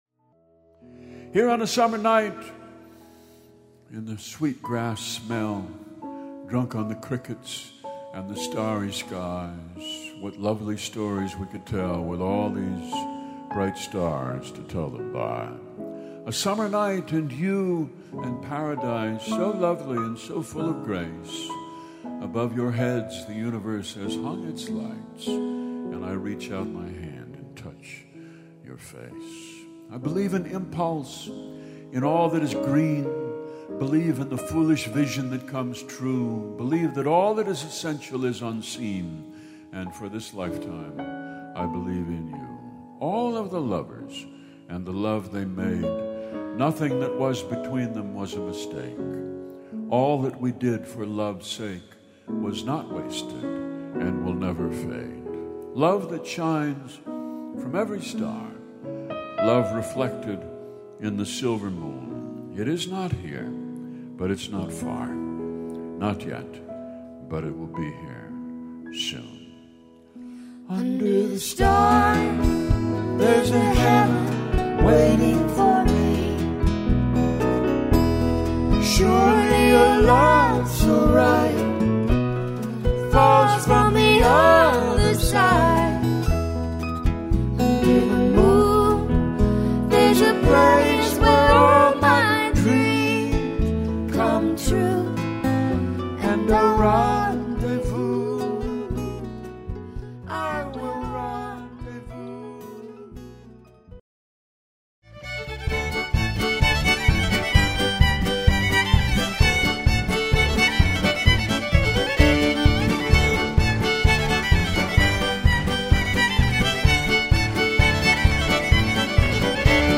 Listen to the May 5, 2007, show This week on A Prairie Home Companion — a special springtime poetry show with Allen Ginsberg reading the words of Walt Whitman, Meryl Streep reading the words of Wendell Berry and Mary Oliver, and Billy Collins and Rita Dove reading their own words.